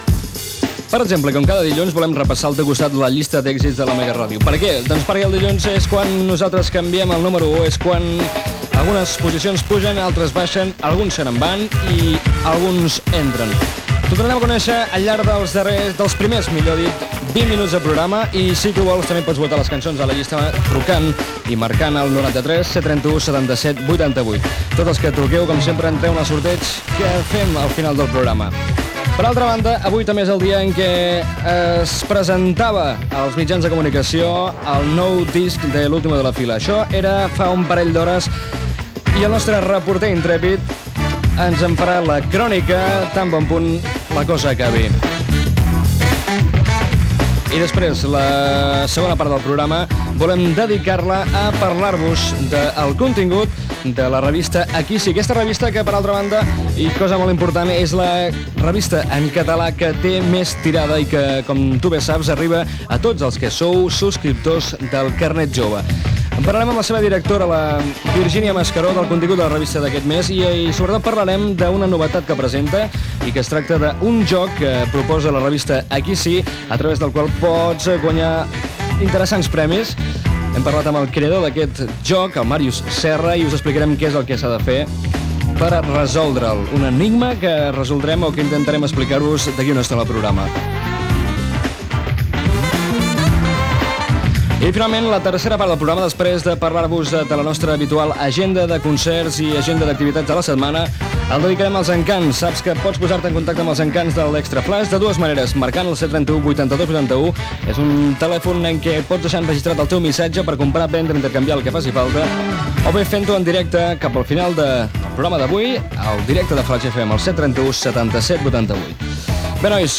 Sumari de continguts i tema musical
Entreteniment